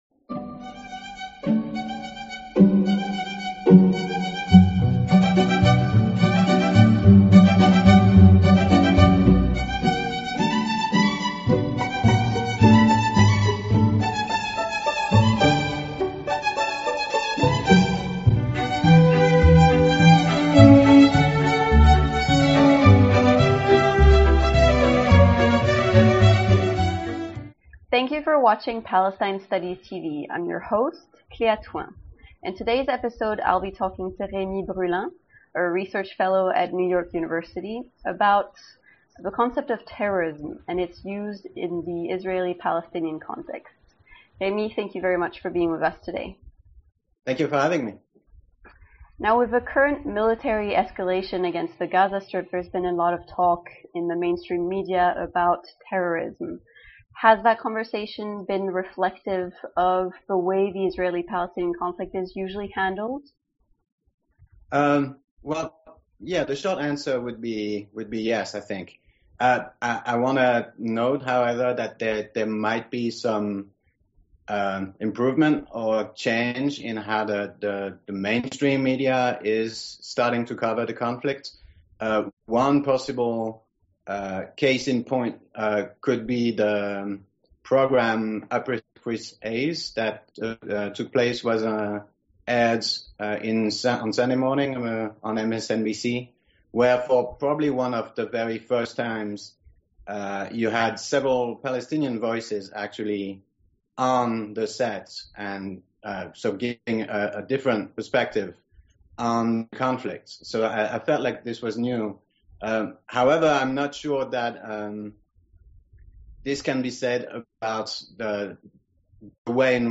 We begin with a 5 minute echo of the corporate media dialogue surrounding the enemy image of the "Muslim terrorist", a 2015 interview of Kim Howells, a former chair of the UK Parliamentary Committee which has nominal oversight of the UK's intelligence agencies (MI5, MI6 and GCHQ).